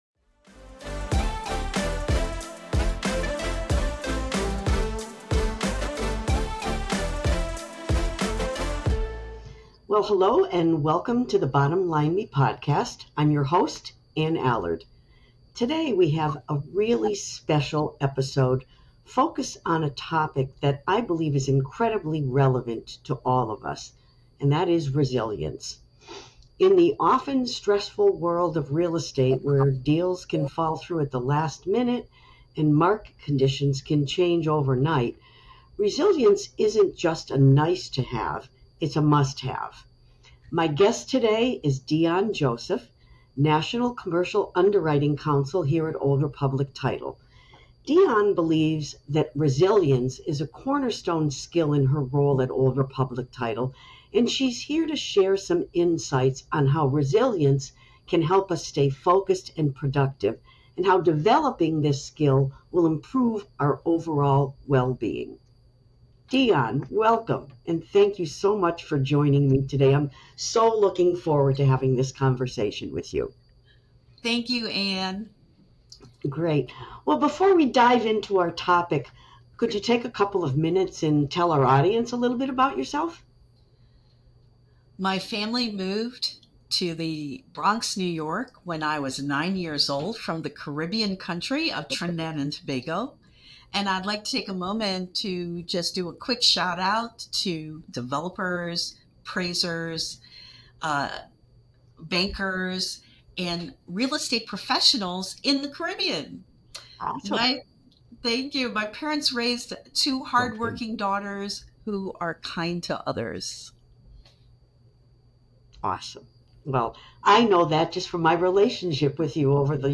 Built to Bounce Back: A Conversation on Resilience